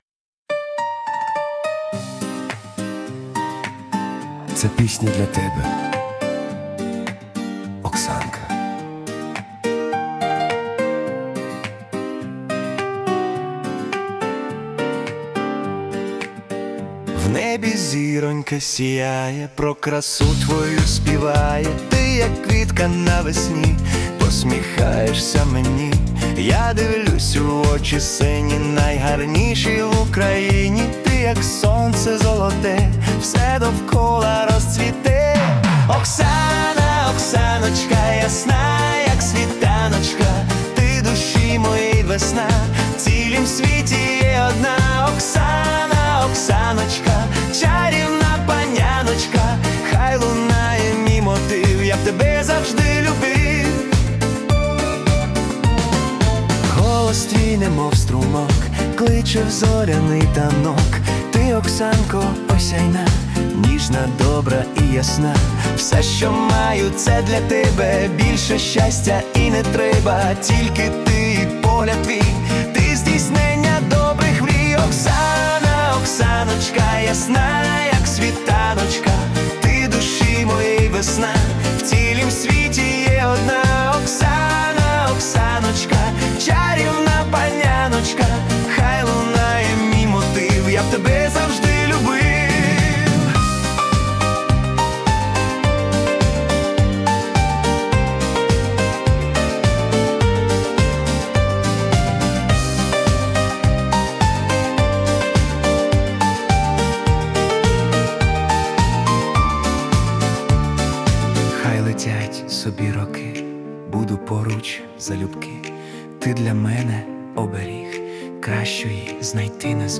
це неймовірно мелодійна та тепла поп-балада
Музична "лампова" атмосфера